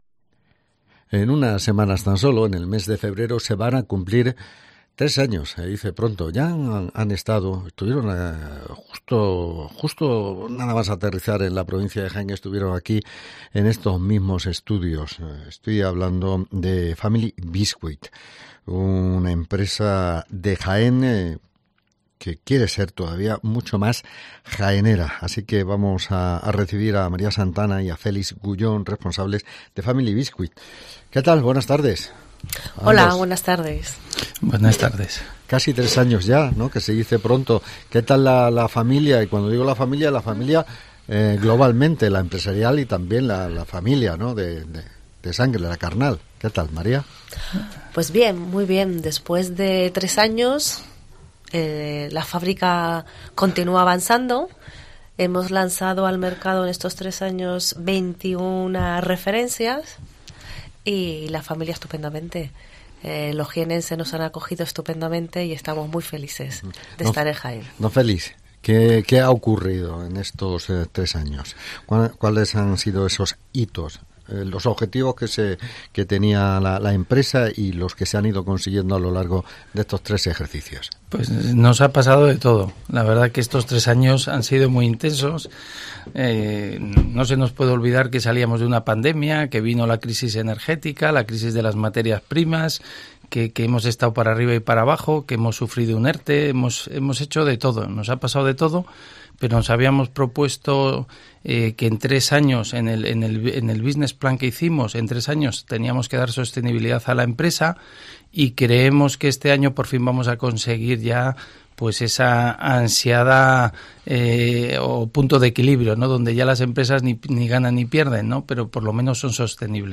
Entrevista con Family Biscuits